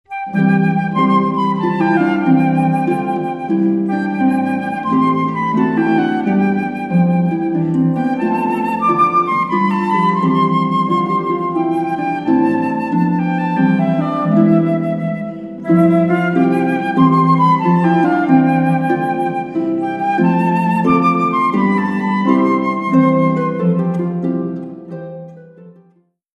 Christmas, Classical